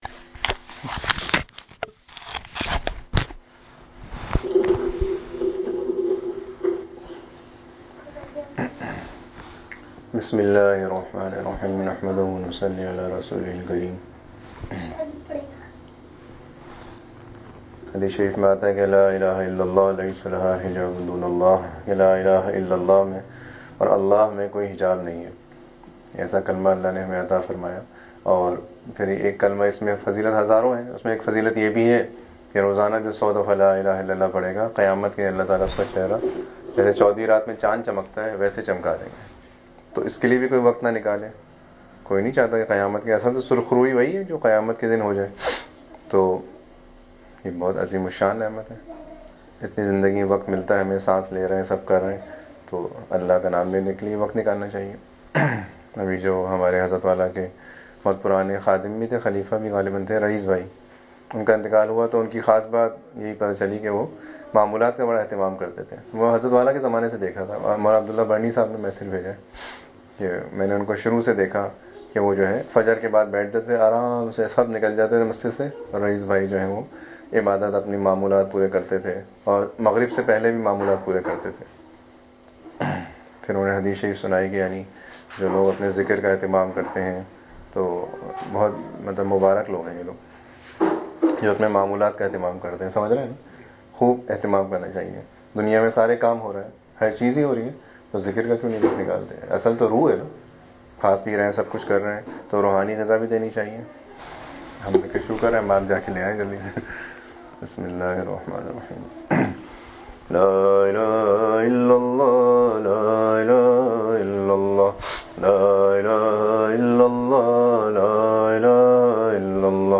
25.1.26 Buhut Khaas Bayan farmaya (Shiekh say Wafadaari ,SADA BARAEY PURANAY MUREEDEEN)